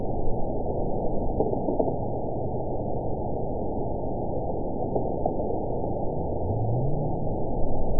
event 910616 date 01/23/22 time 04:29:25 GMT (3 years, 3 months ago) score 9.42 location TSS-AB01 detected by nrw target species NRW annotations +NRW Spectrogram: Frequency (kHz) vs. Time (s) audio not available .wav